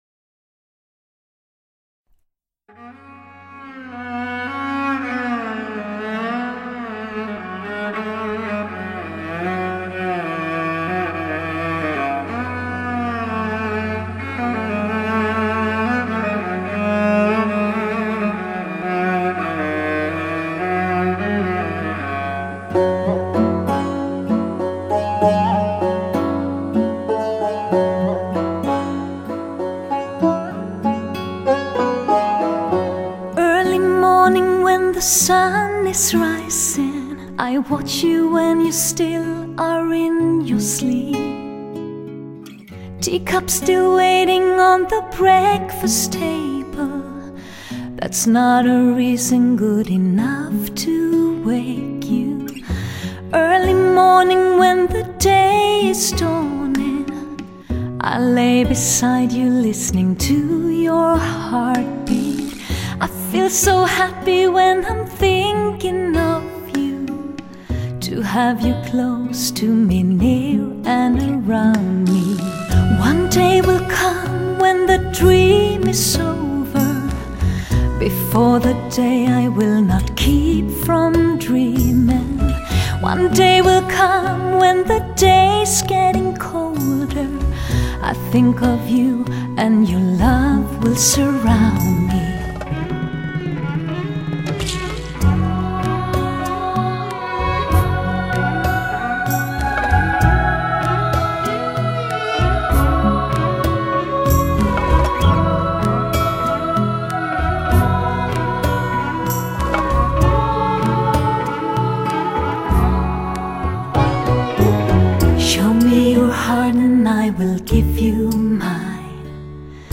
首位签约中国唱片的欧洲著名歌星完美声线超级演泽，华语经典歌曲英文版。